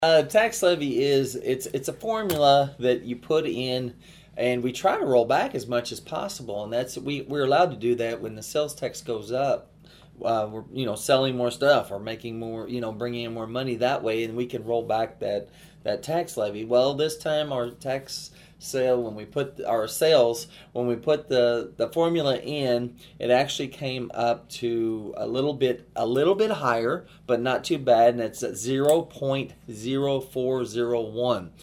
County Commissioner David Kater explains how the tax levy works.